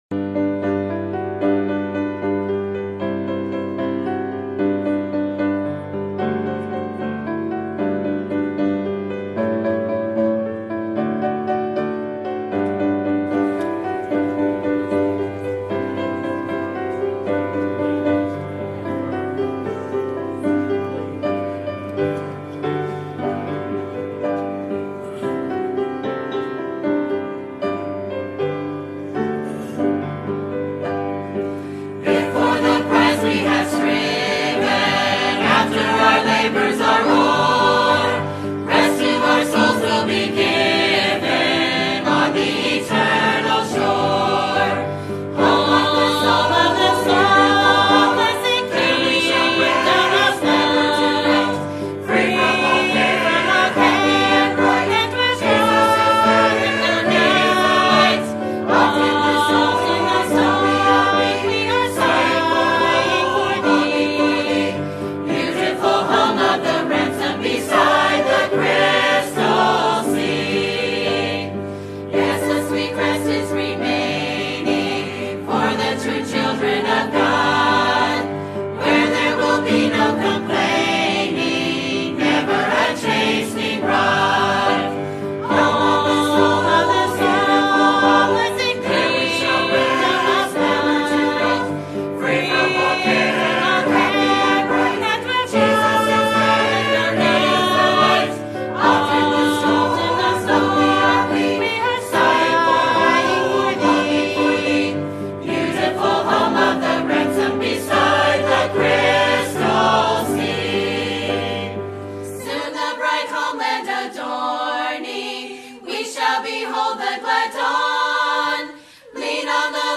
Sermon Topic: 50th Anniversary Sermon Type: Special Sermon Audio: Sermon download: Download (22.87 MB) Sermon Tags: Joshua Anniversary Sin Trespass